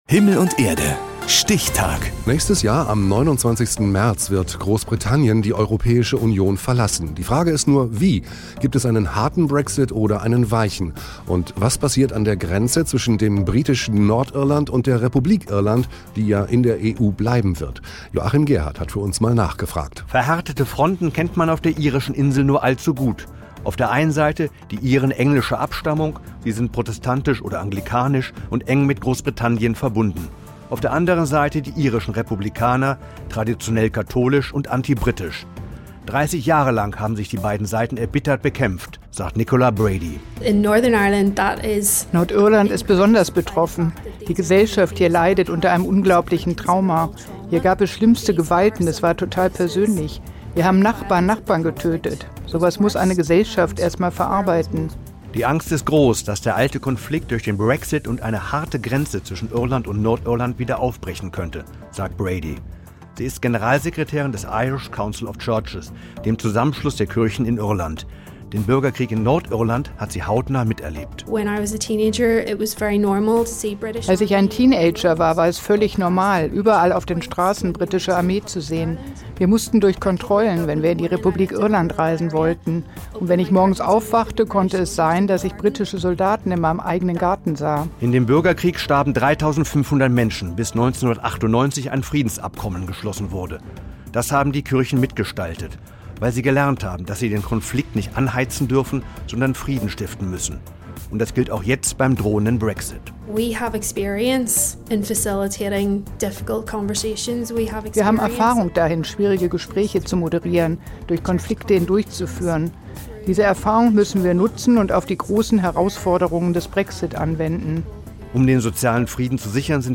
Der Brexit war allgegenwärtig wie auch noch der alte Nordirland-Konflikt. Dazu ein Beitrag auf Radio Bonn/Rhein-Sieg.